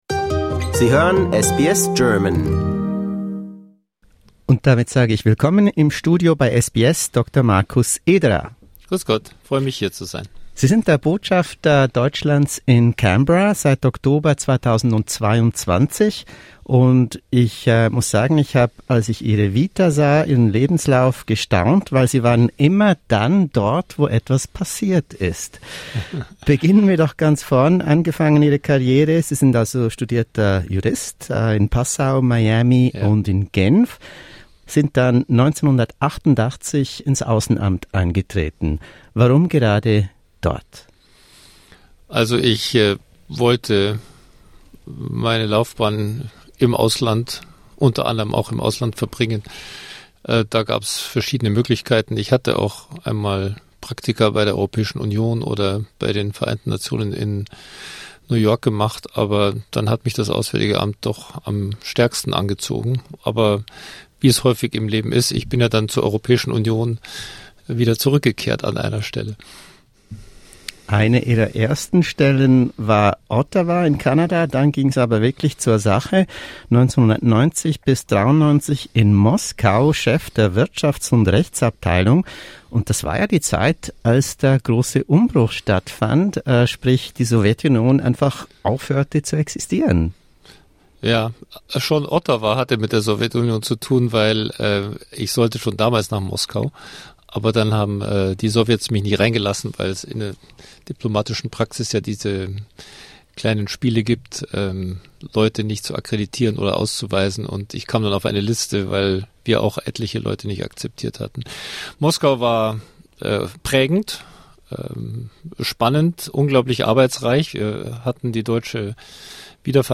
Interview with German Ambassador in Australia